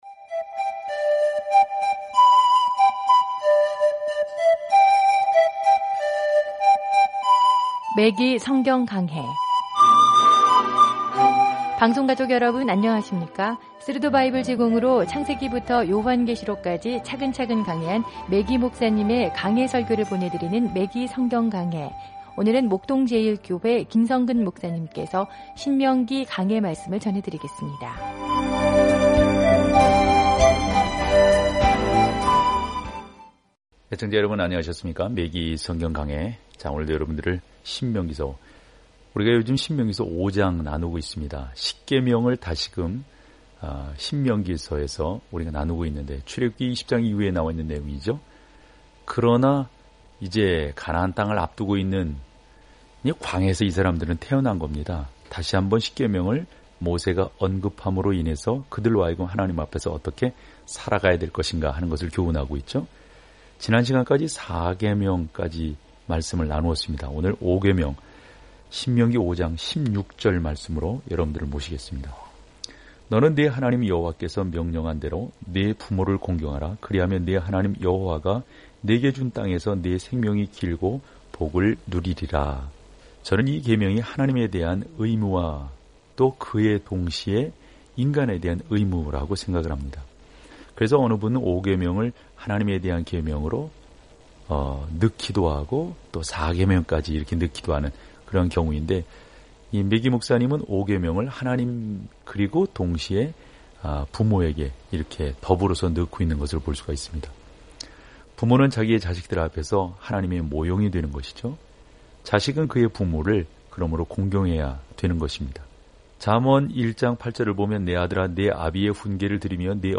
말씀 신명기 5:16-33 신명기 6:1-2 5 묵상 계획 시작 7 묵상 소개 신명기는 하나님의 선한 율법을 요약하고 순종이 그분의 사랑에 대한 우리의 반응이라고 가르칩니다. 오디오 공부를 듣고 하나님의 말씀에서 선택한 구절을 읽으면서 매일 신명기를 여행하세요.